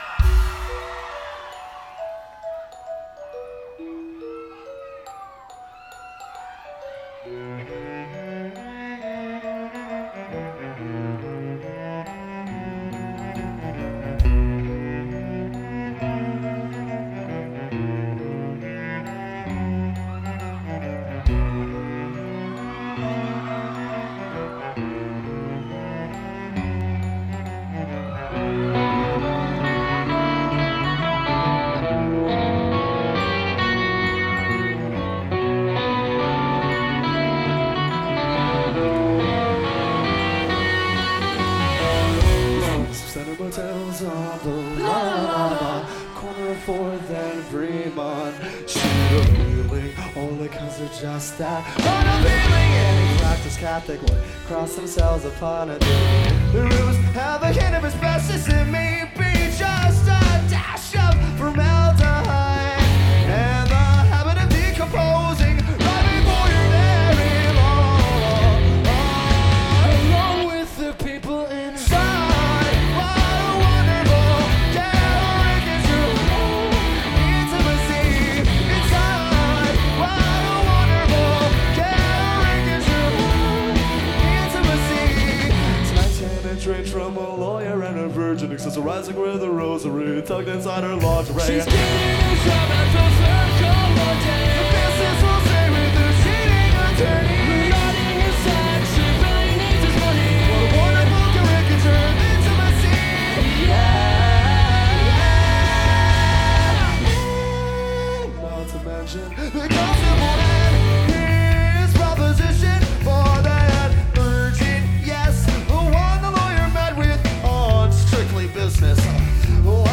Live in Denver